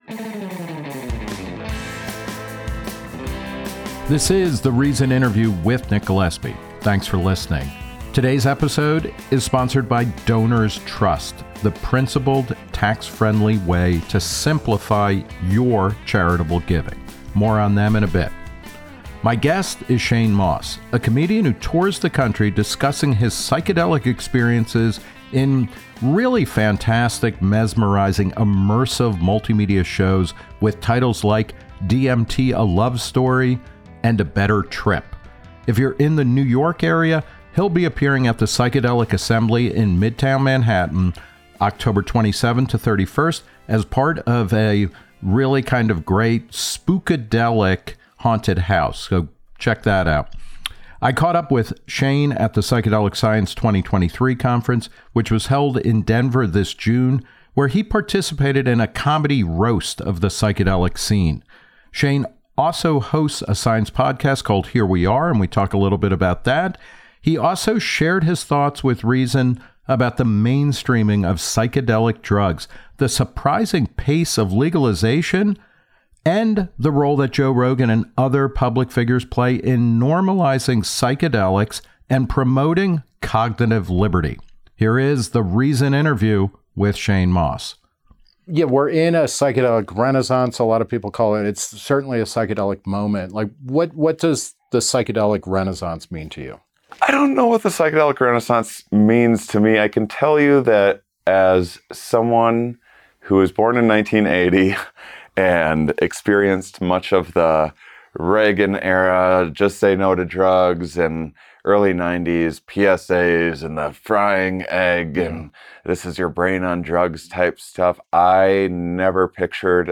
I caught up with him at the Psychedelic Science 2023 conference, held in Denver this June, where he participated in a "roast" of the psychedelic scene.